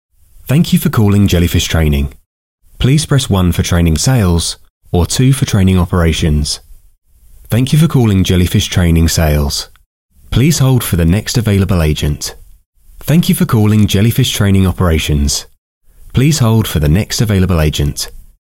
IVR System – Jellyfish Training
BRITISH MALE VOICE-OVER ARTIST
Warm, neutral (non-regional) English accent
JFT-IVR-voiceover.mp3